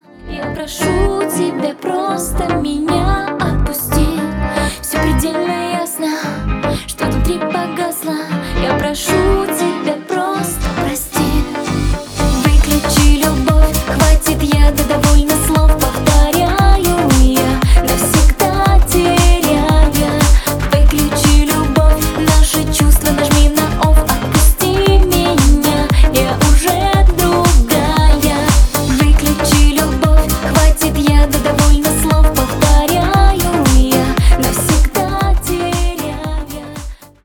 • Качество: 320 kbps, Stereo
Поп Музыка